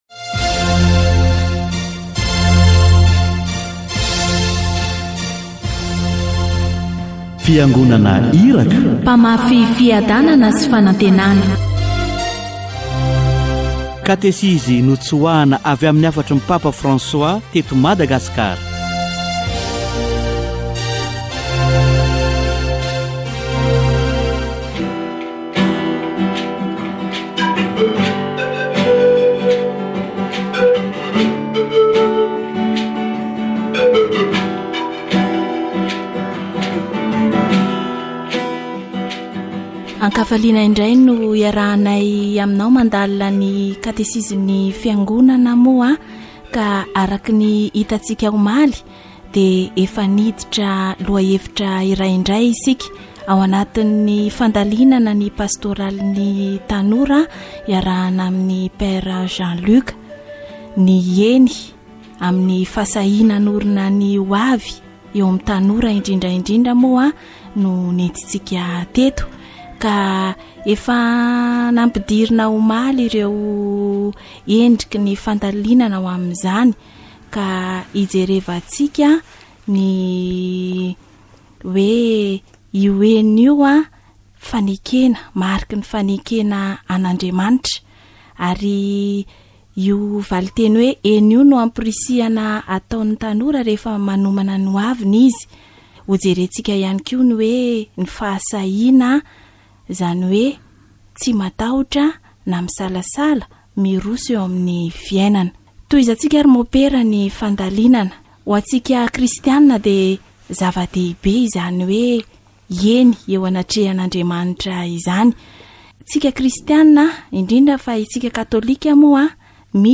Io faneken'i Masina Maria io no faratampony satria fiatombohan'ny asam-panavotana nataon'I Jesoa Kristy teto an-tany. Katesizy momba ny "eny amin'ny fahasahiana hanorina ho avy